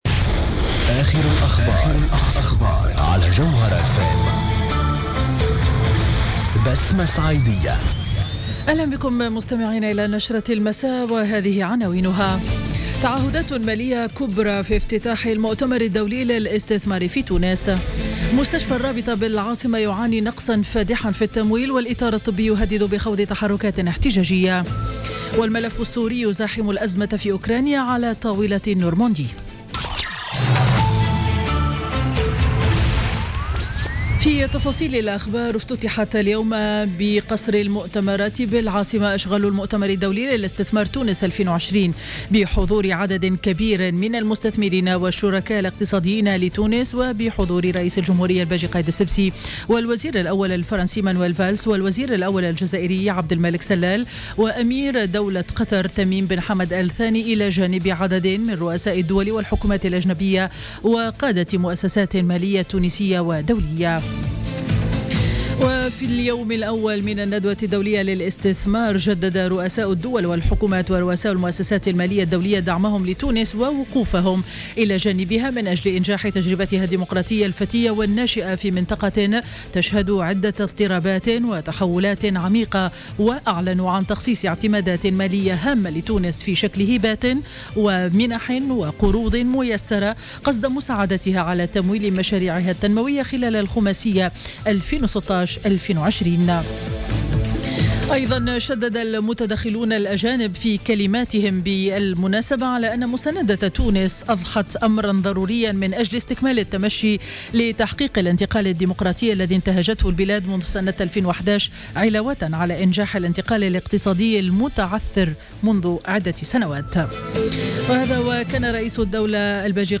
نشرة أخبار السابعة مساء ليوم الثلاثاء 29 نوفمبر 2016